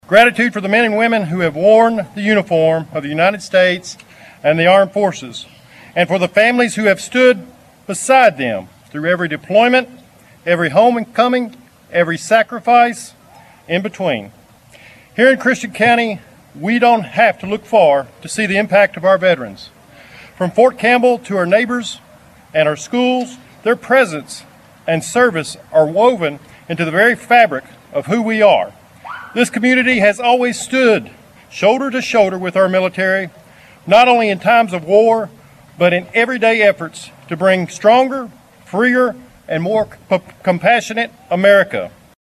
Christian County Judge Executive Jerry Gilliam says it is important to remember the sacrifices of our veterans and their families.